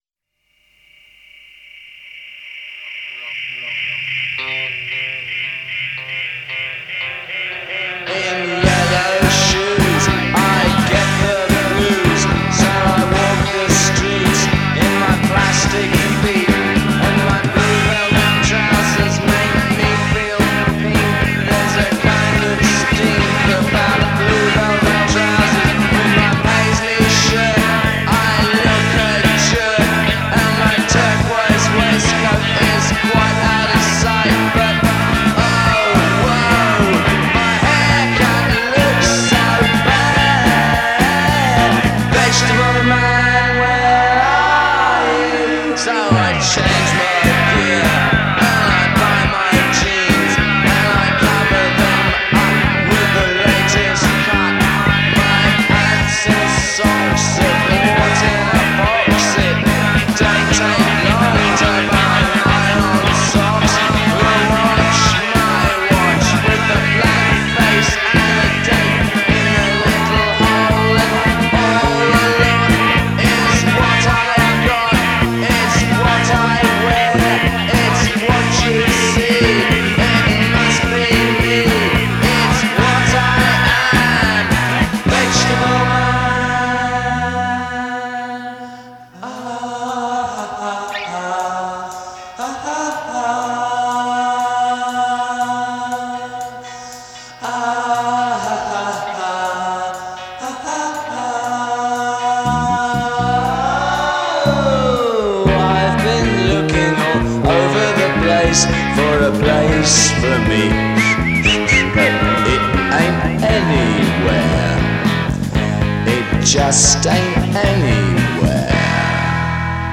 super psychedelic